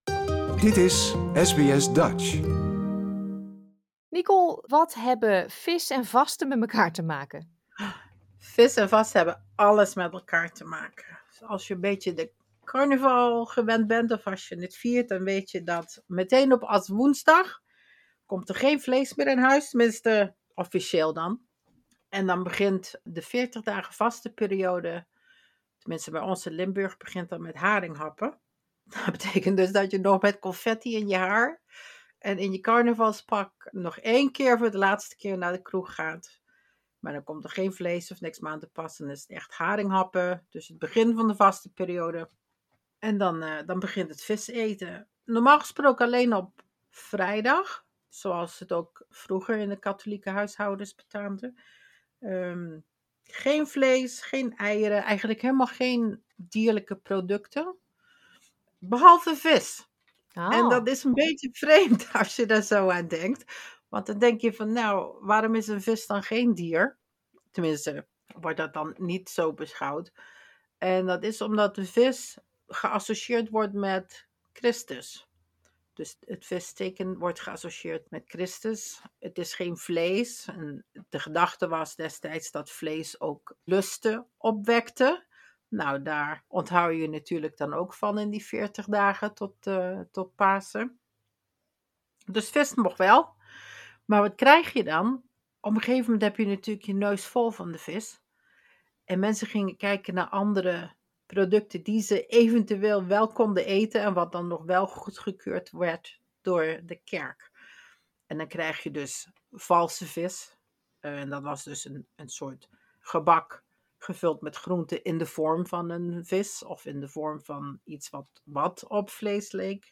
This interview is in Dutch.